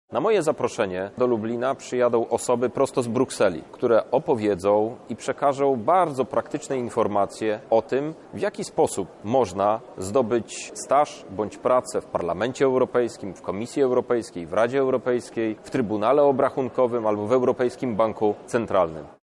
Na spotkanie przyjadą specjalni goście – podkreśla europoseł Krzysztof Hetman.